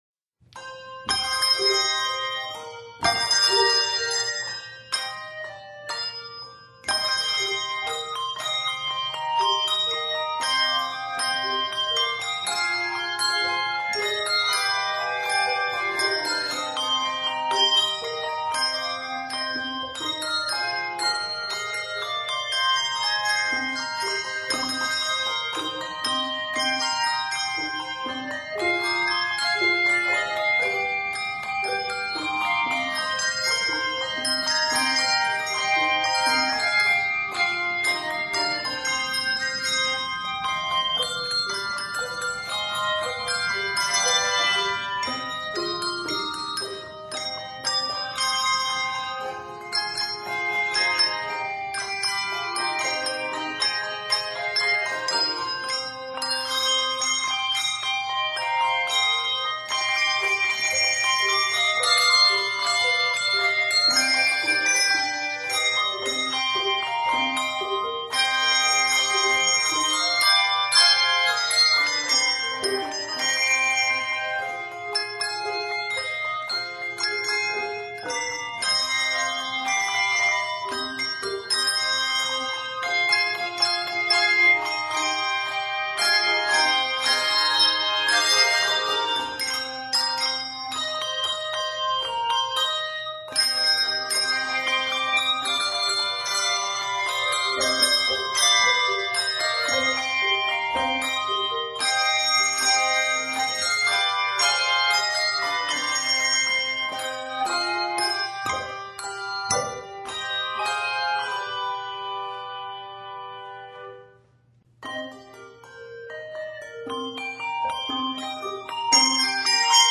is arranged in c minor.